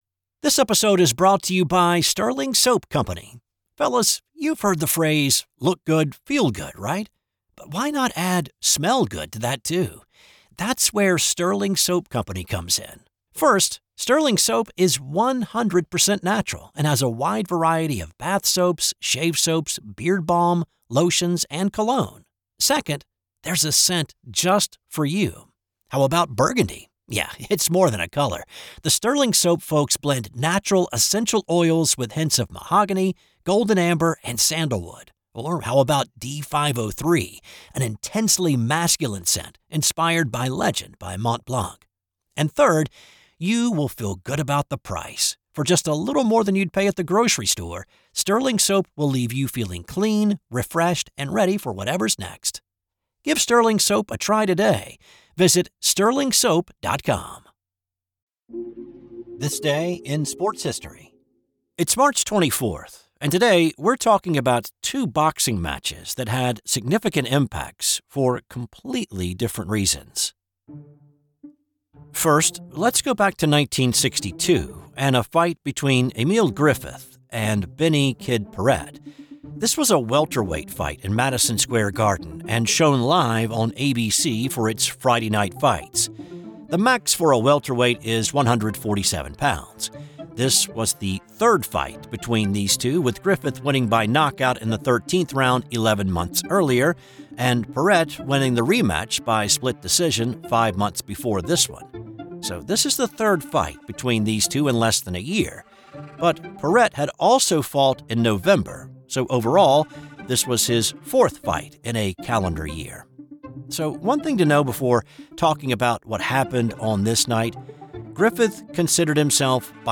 In 2001, a Randy Johnson fastball struck a bird in flight in a spring training game. 'This Day in Sports History' is a one PERSON operation. I research, write, voice, and produce each show.